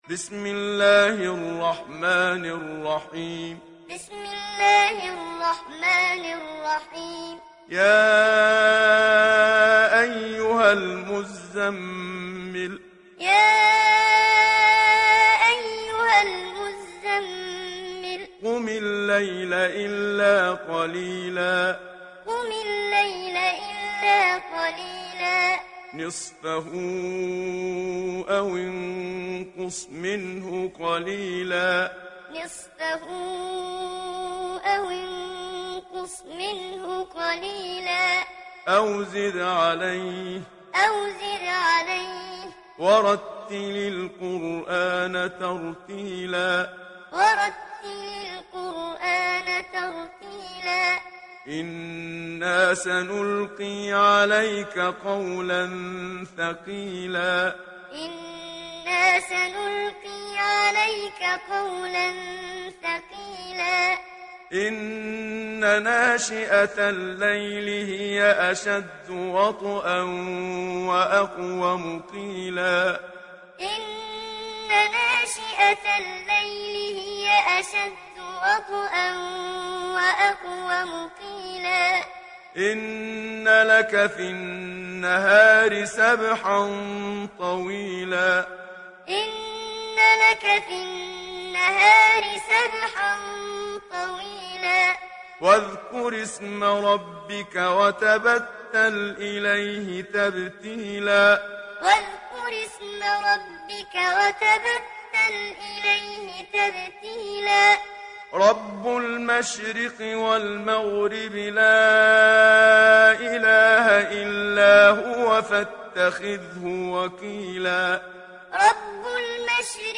Sourate Al Muzzammil Télécharger mp3 Muhammad Siddiq Minshawi Muallim Riwayat Hafs an Assim, Téléchargez le Coran et écoutez les liens directs complets mp3